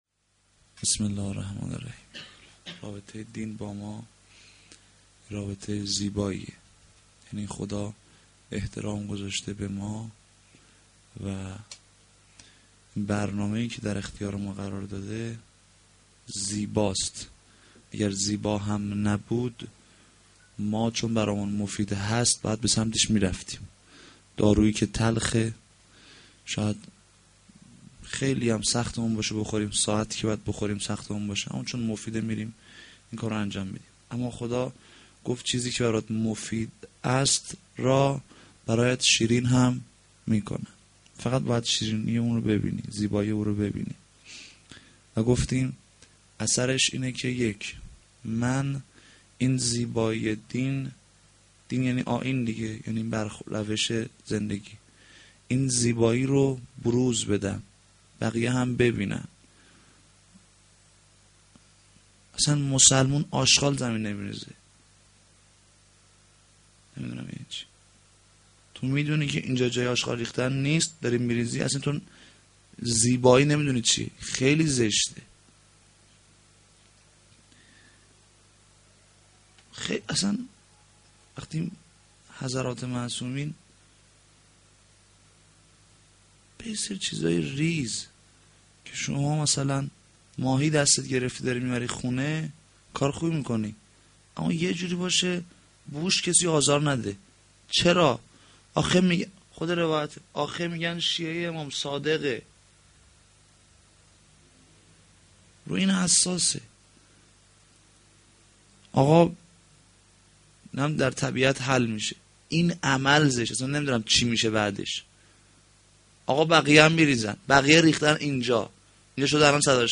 sh-10-moharram-92-sokhanrani.mp3